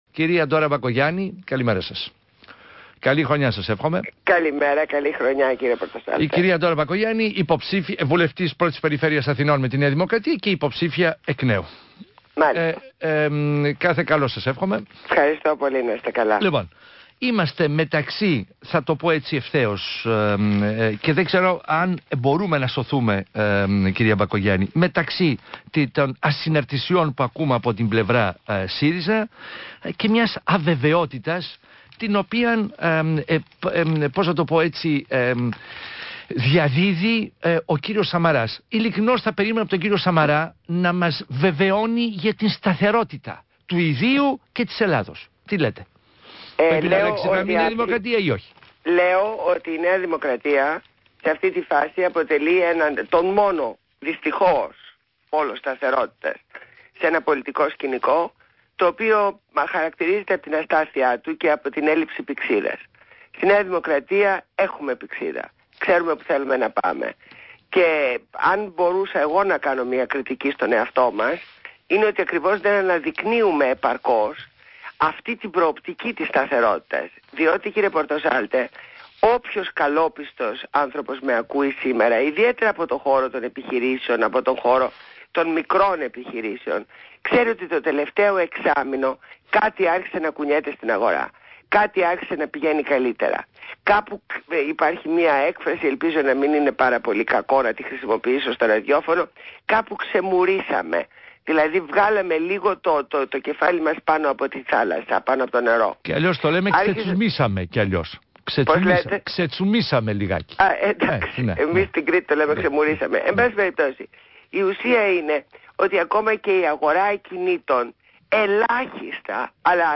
Συνέντευξη στο ραδιόφωνο του ΣΚΑΪ, στο δημοσιογράφο Α. Πορτοσάλτε.